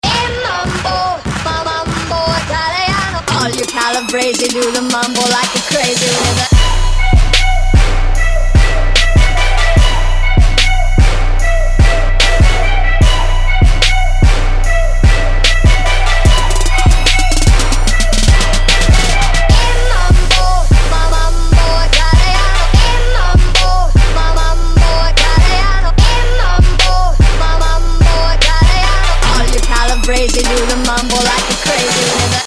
• Качество: 160, Stereo
трэп